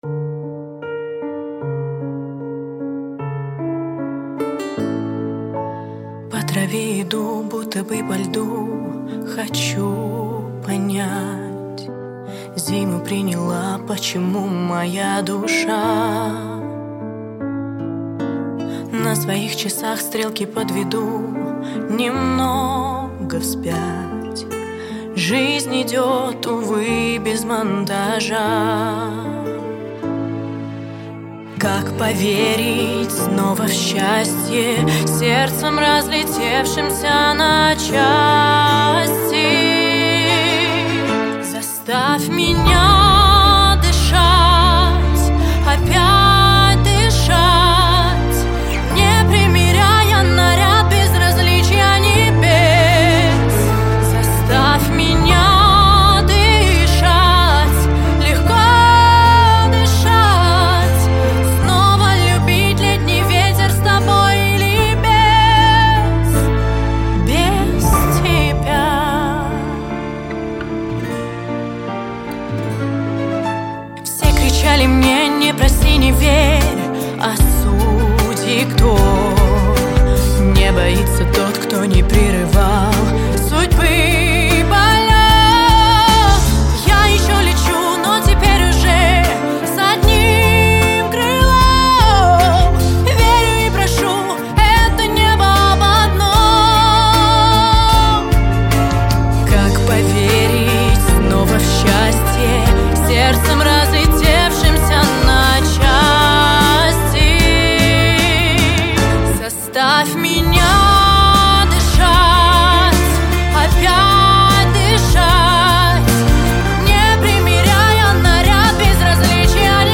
Певческий голос Сопрано